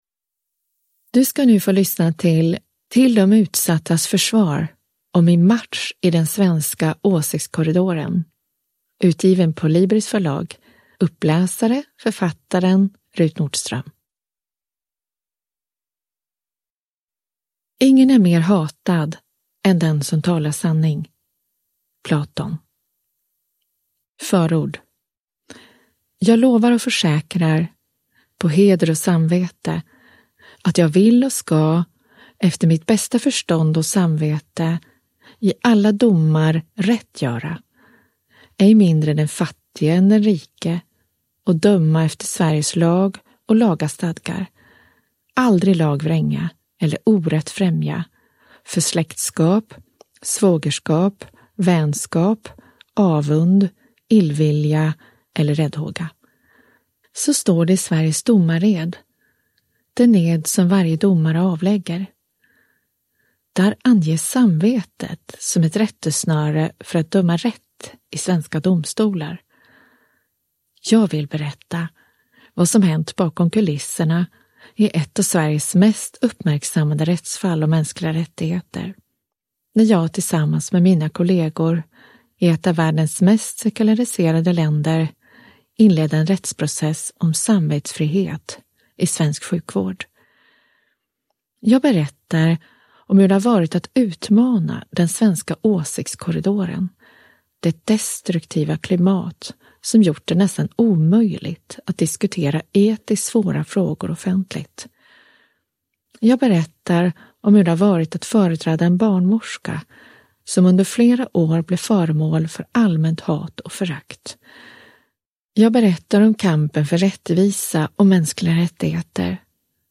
Till de utsattas försvar: om min match i åsiktskorridoren – Ljudbok – Laddas ner